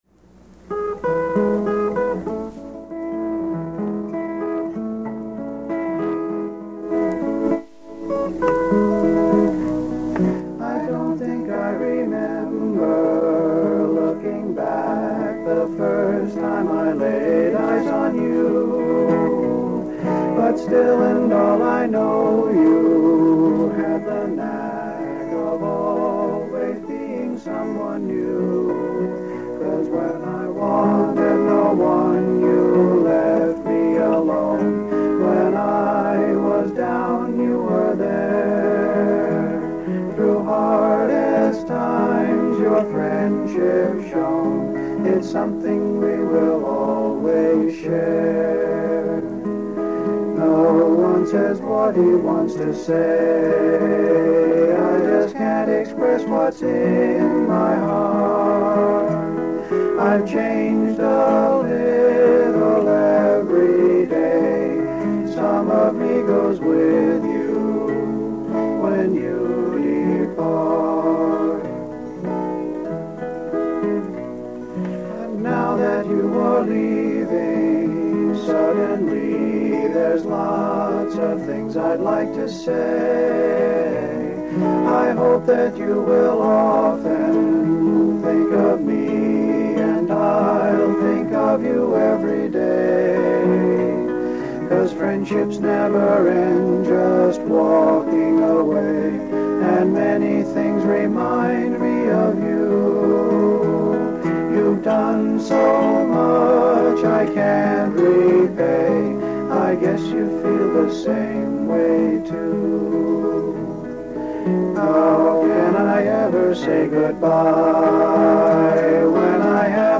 All the voices are mine, using now- ancient overdubbing technology of a Tandberg reel-to-reel tape deck.